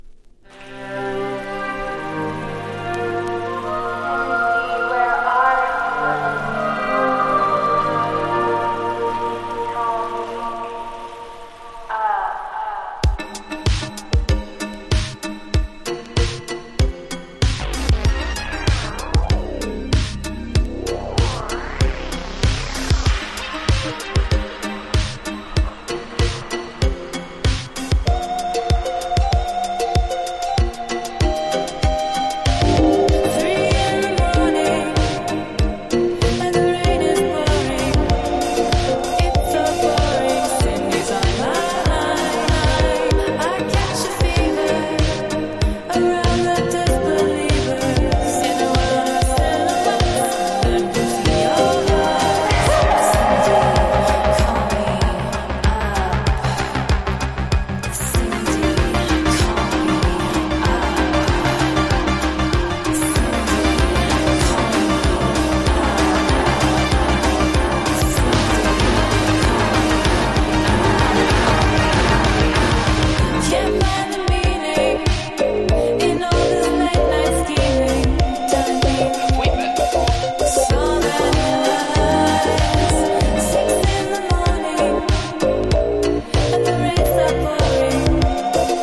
AORをそのまま現代のダンス・ミュージックにしてしまったかの様な爽快感のあるシンセ・ディスコ・サウンド作。
INDIE DANCE# ELECTRO POP